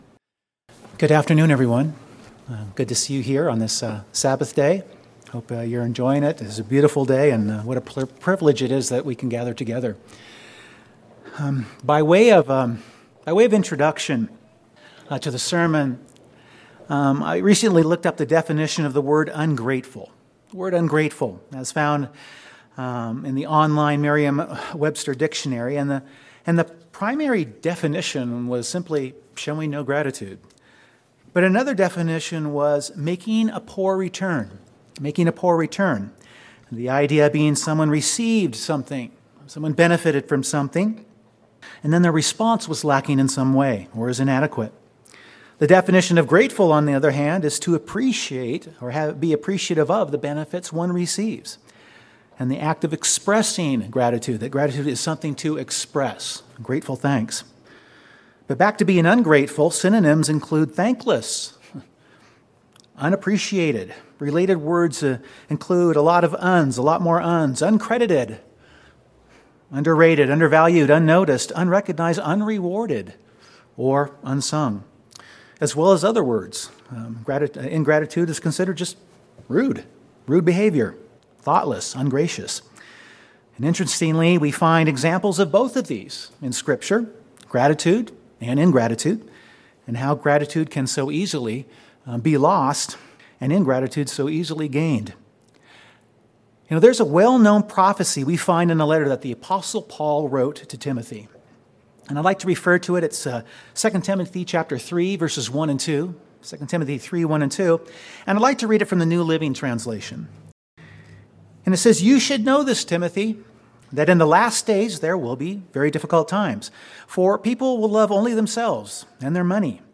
The sermon focuses on four examples in scripture where the humble expression of thanks was central to the example of character of the people involved, how we are to follow it, and to answer the question; do we live our lives through the eyes of gratitude?